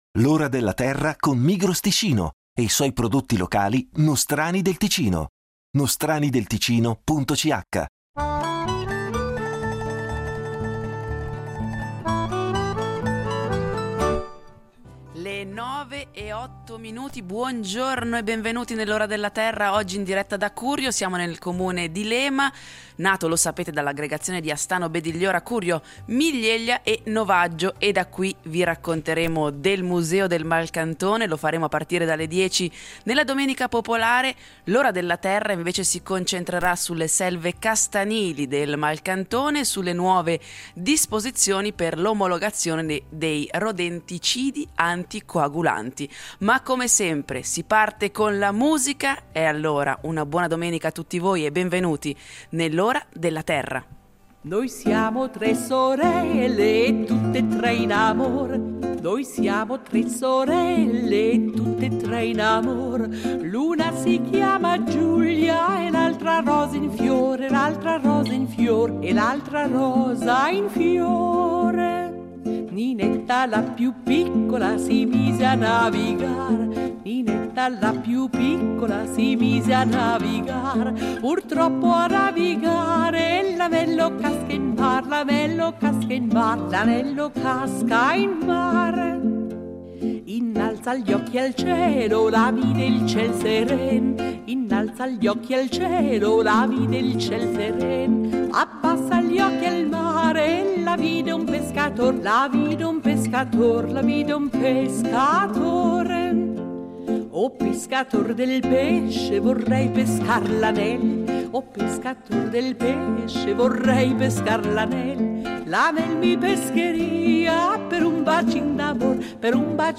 L’Ora della Terra va in scena da Curio, quartiere del neonato comune di Lema. In diretta dal Museo del Malcantone , vi racconteremo delle selve castanili orientate del Malcantone e delle nuove disposizioni per l’omologazione dei rodenticidi anticoagulanti.